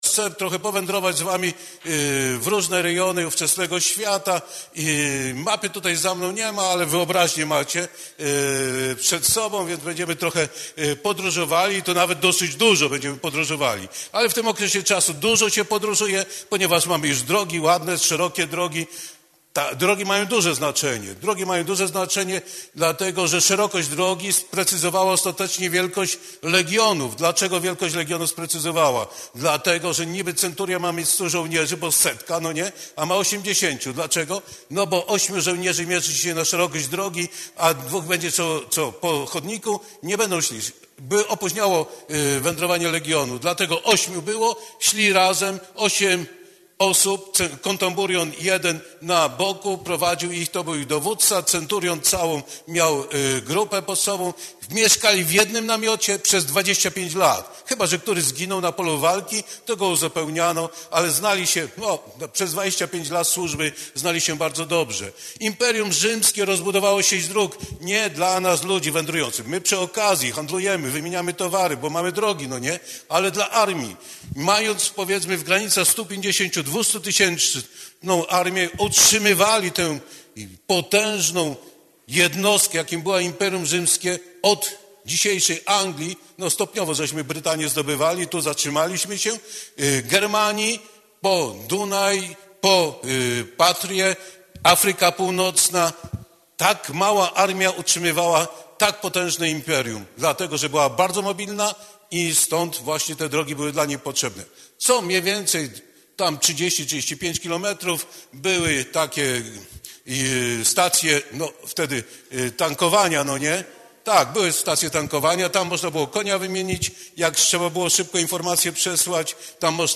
W sobotę 26 października 2024 roku odbył się drugi zjazd, w tym roku akademickim, Uniwersytetu Trzeciego Wieku im. Jana Pawła II Papieskiego Wydziału Teologicznego we Wrocławiu.
wykłady Aula PWT